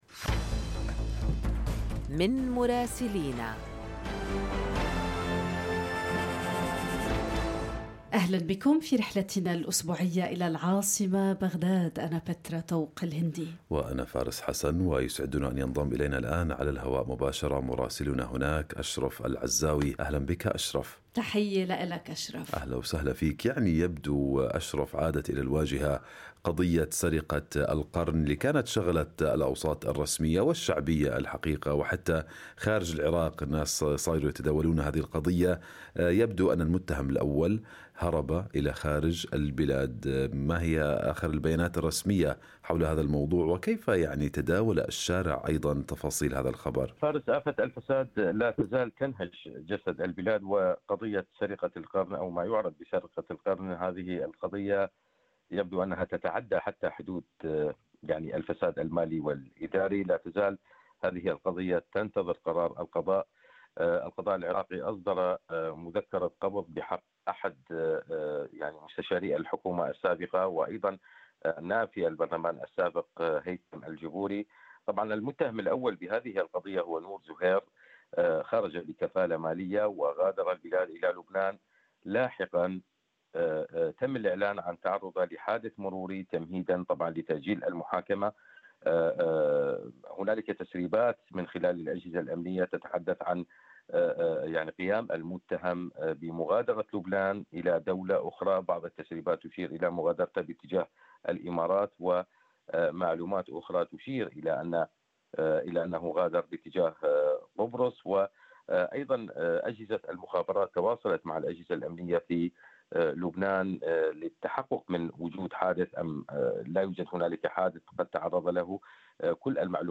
المزيد من التفاصيل في التقرير الصوتي أعلى الصفحة.